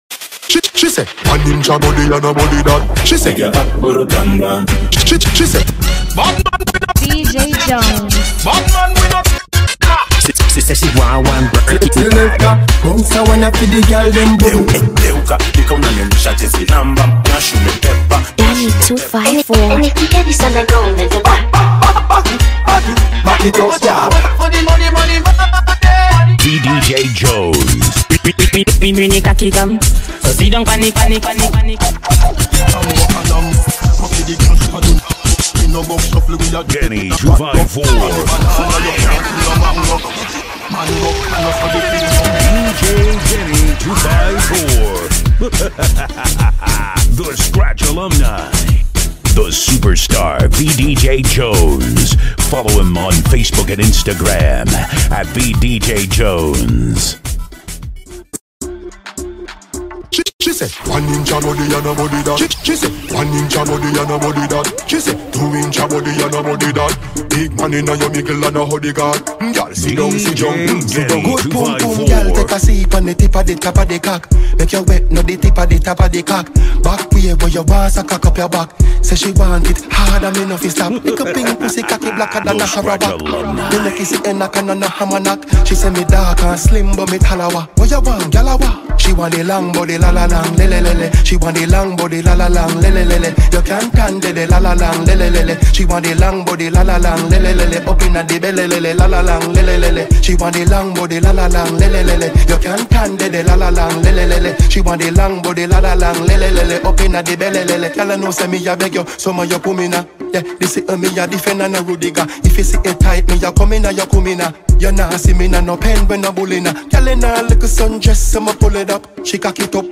No pauses. No skips. Just pure dancehall energy